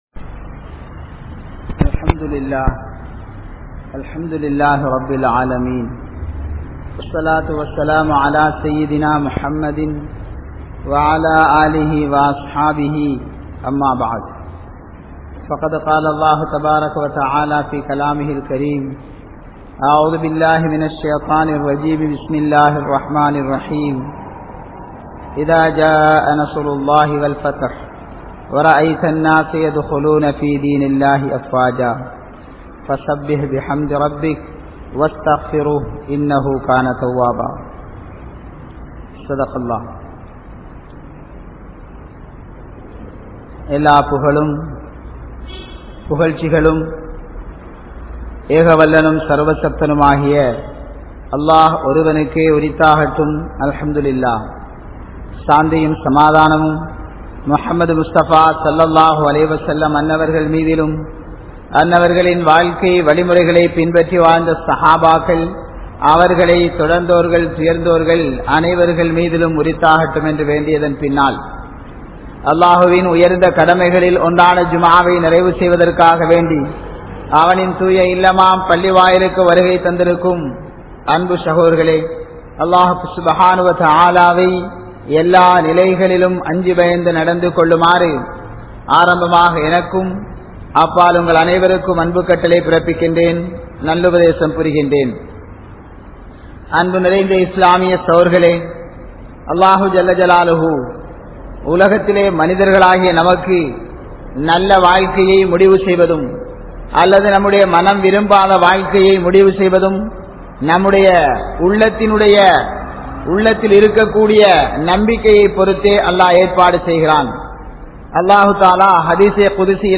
Nantri Ullavaraaha Irungal (நன்றி உள்ளவராக இருங்கள்) | Audio Bayans | All Ceylon Muslim Youth Community | Addalaichenai